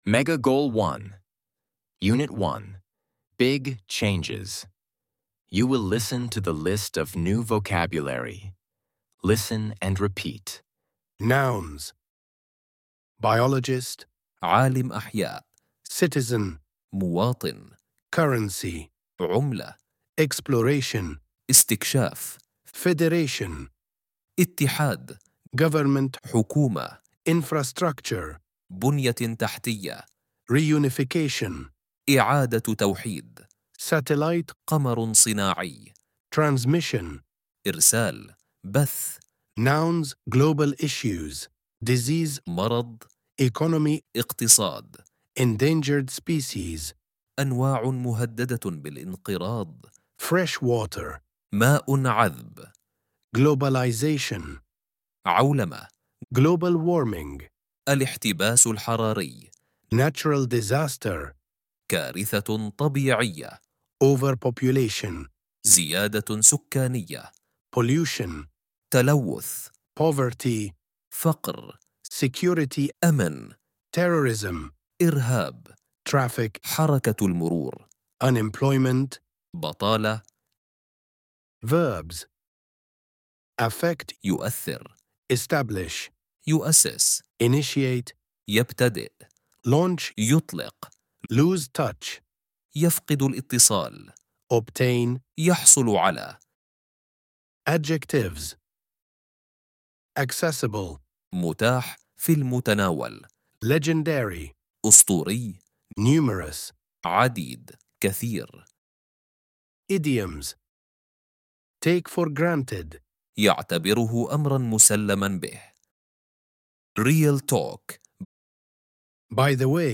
ElevenLabs_Mega_Goal_1_Unit_1_Vocabulary.mp3